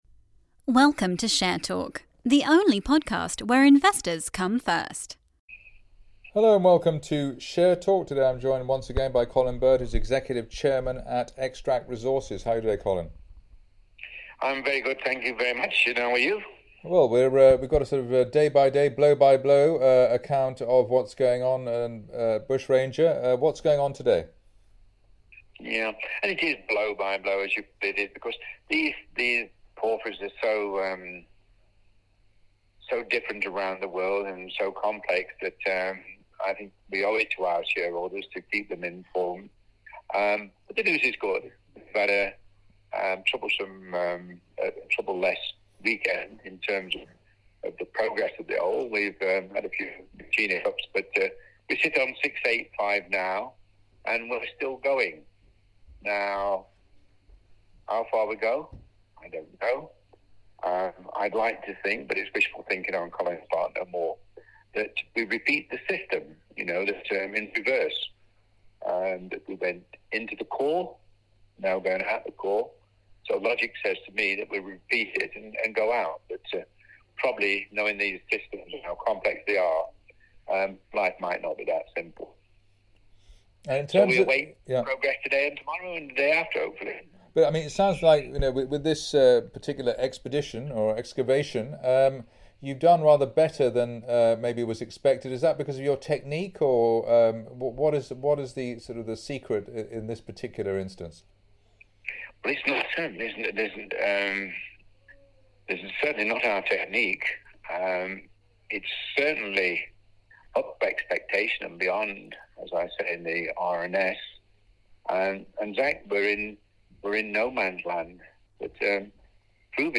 Xtract Resources plc (XTR.L) Bushranger Drilling Programme Progress/Interview